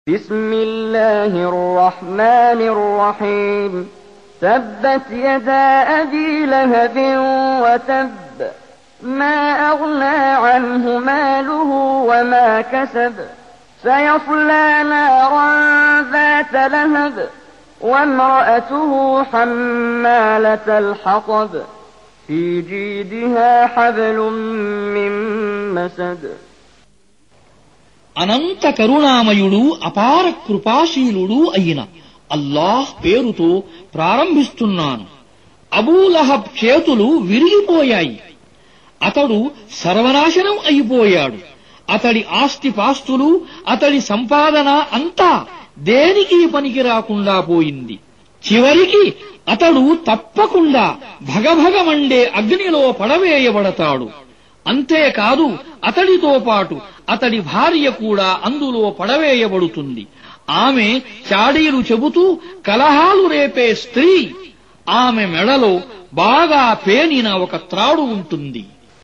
Surah Repeating تكرار السورة Download Surah حمّل السورة Reciting Mutarjamah Translation Audio for 111. Surah Al-Masad سورة المسد N.B *Surah Includes Al-Basmalah Reciters Sequents تتابع التلاوات Reciters Repeats تكرار التلاوات